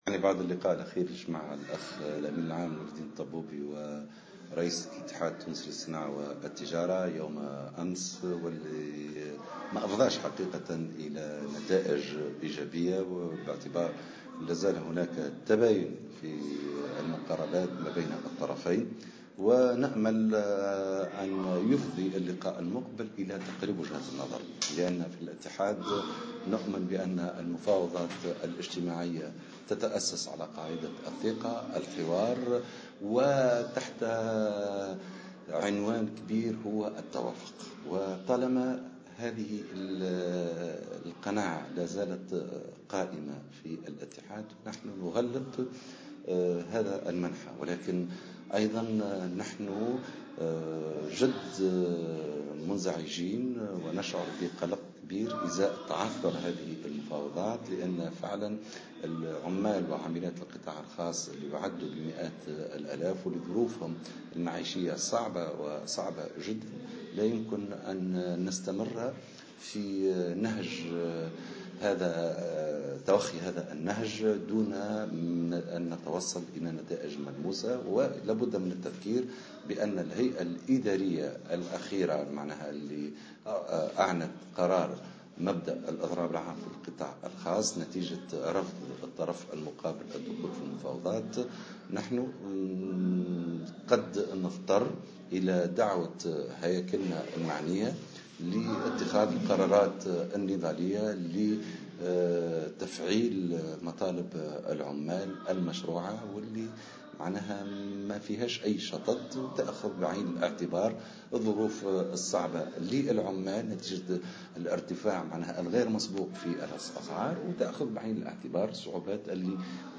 على هامش مائدة مستديرة نظمها الاتحاد الجهوي للشغل بسوسة